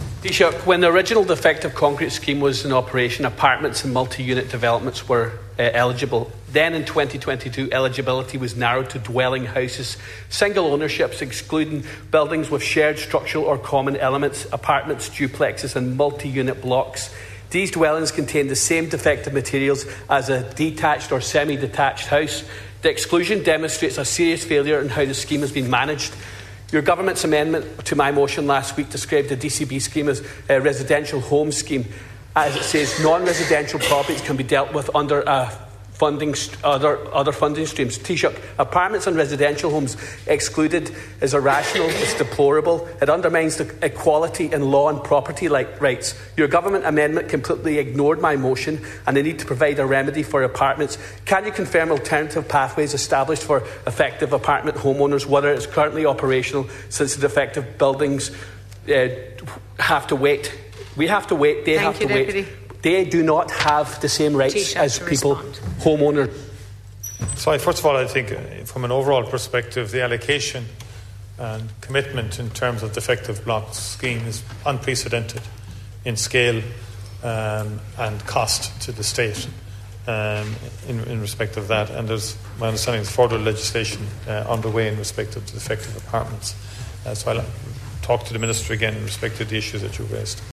Deputy Ward told the Dail that this is discriminatory, and denies apartment owners their rights………